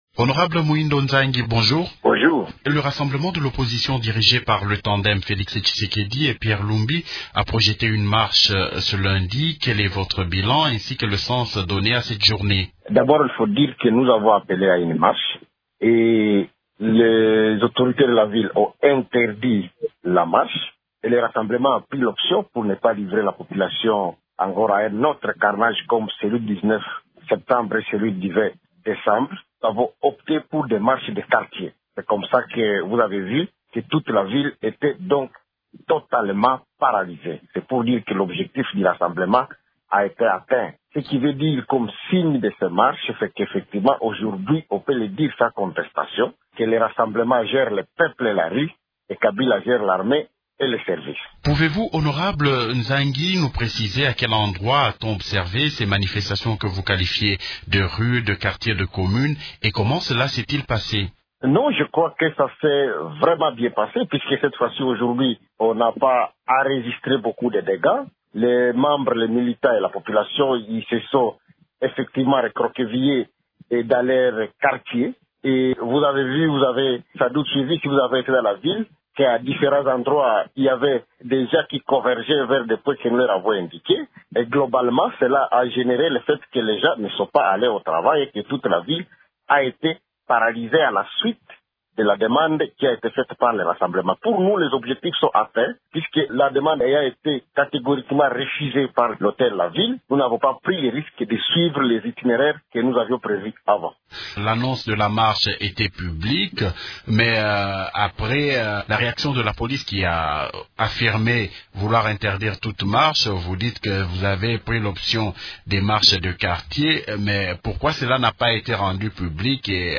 Muhindo Nzangi est l’invité de Radio Okapi mardi 11 avril.